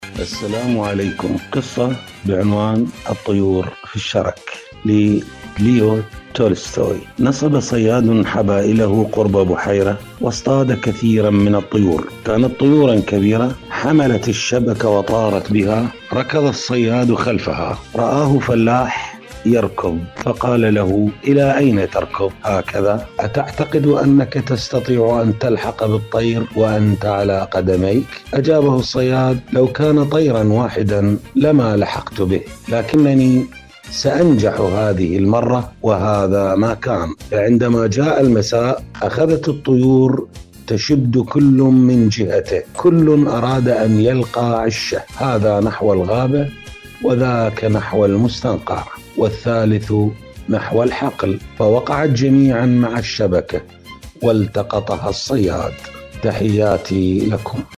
إذاعة طهران- المنتدى الإذاعي